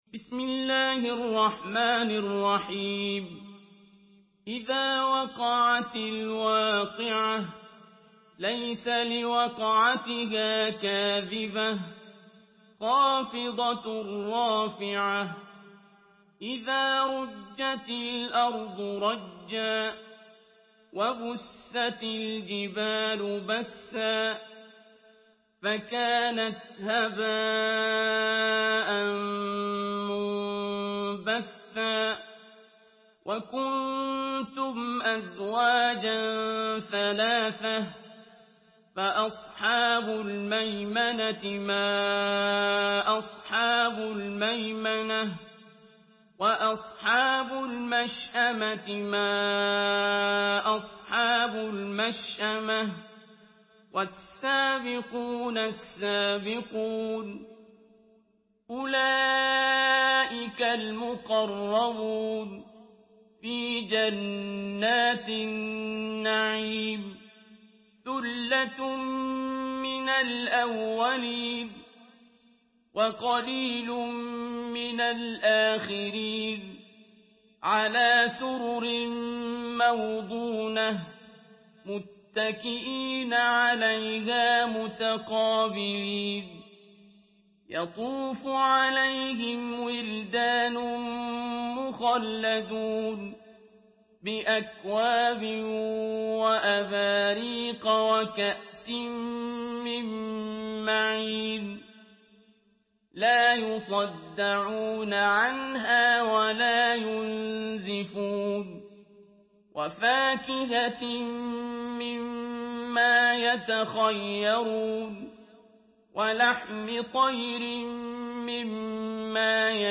Surah Vakia MP3 by Abdul Basit Abd Alsamad in Hafs An Asim narration.
Murattal Hafs An Asim